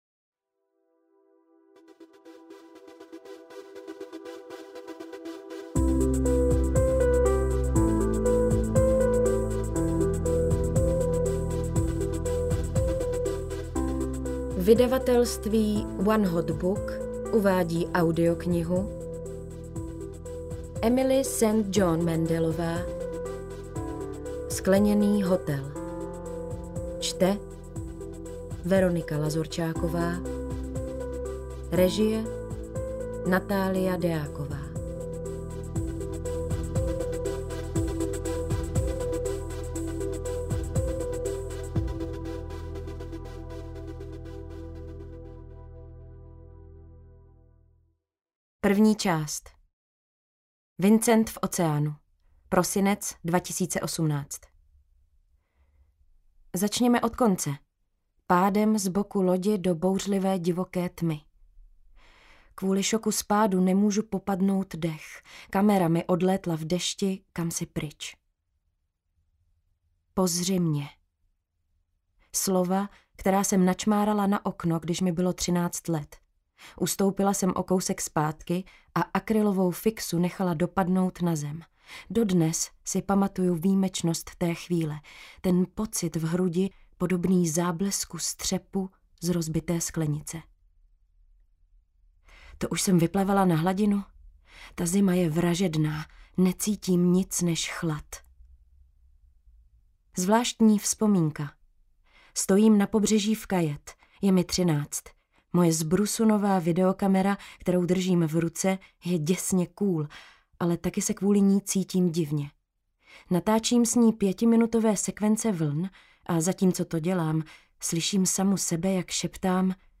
AudioKniha ke stažení, 28 x mp3, délka 10 hod. 58 min., velikost 602,4 MB, česky